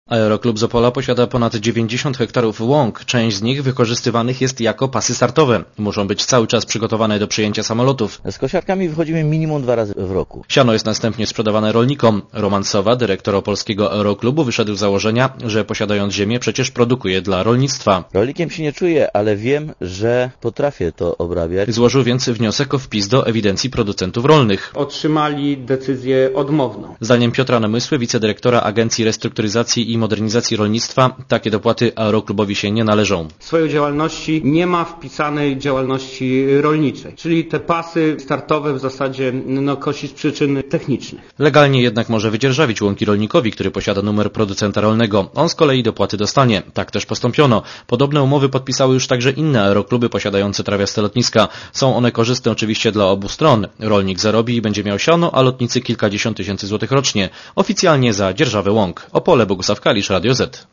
Szefostwo aeroklubu wydzierżawiło więc teren rolnikowi, który będzie zabiegał o dofinansowanie gruntów rolnych. Źródło: RadioZet Relacja reportera Radia ZET Oceń jakość naszego artykułu: Twoja opinia pozwala nam tworzyć lepsze treści.